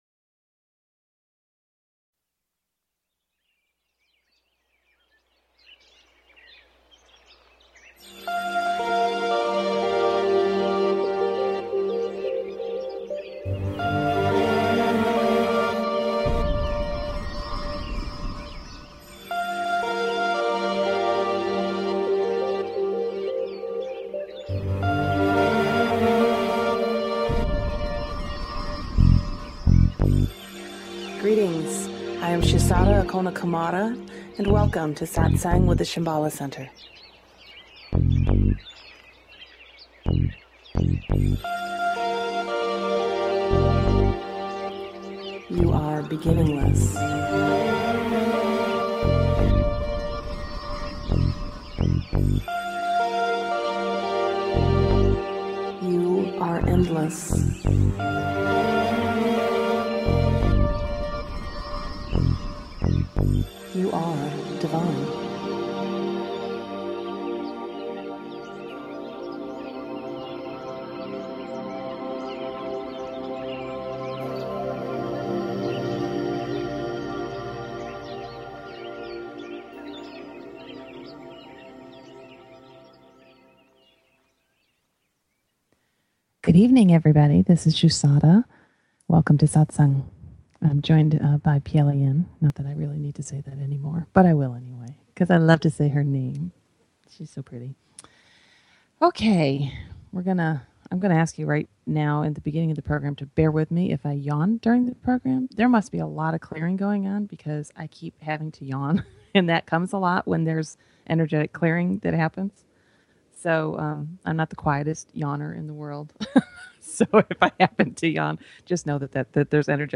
Talk Show Episode, Audio Podcast
Tonight's session began with a guided meditation revoloving aroung the requalification of the chakras.